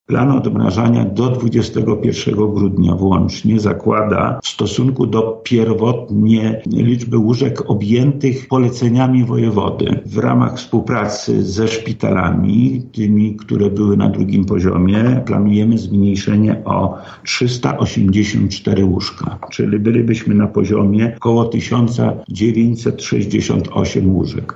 -mówi Wojewoda Lubelski Lech Sprawka.